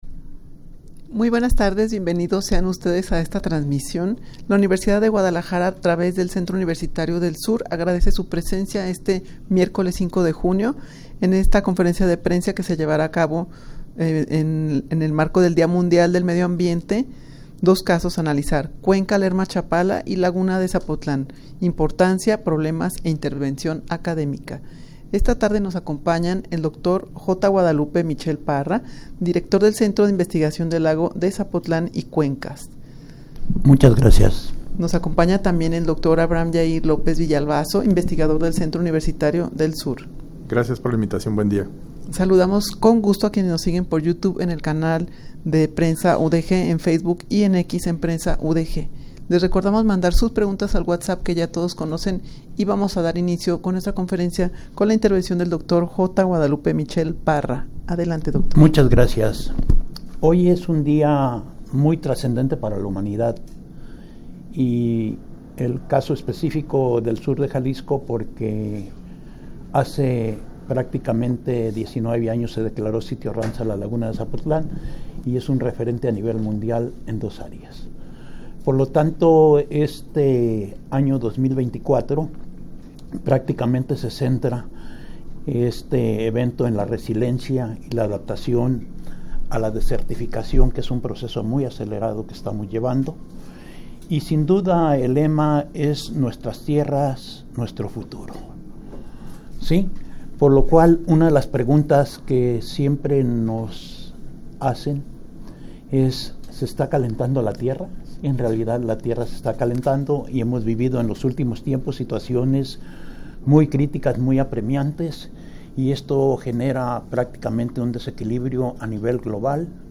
Audio de la Rueda de Prensa
rueda-de-prensa-que-se-lleva-a-cabo-en-el-marco-del-dia-mundial-del-medio-ambiente.mp3